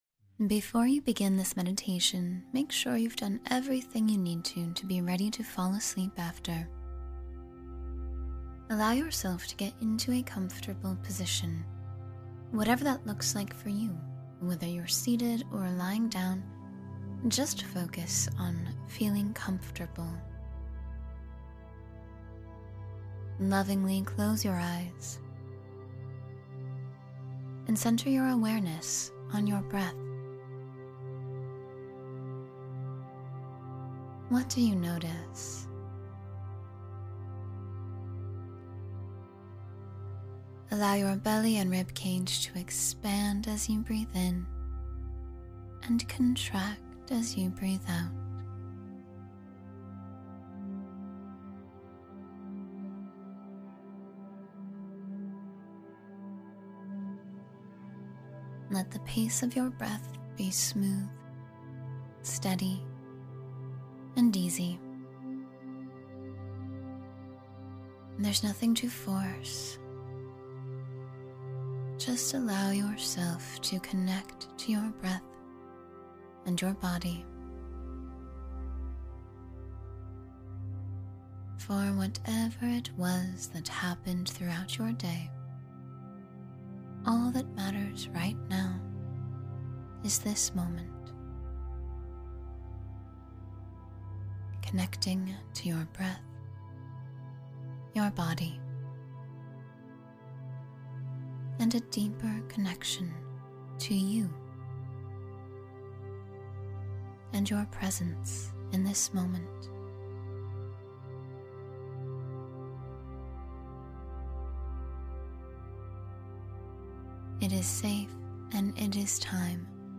Relax and Sleep Deeply in 10 Minutes — Guided Meditation for Rest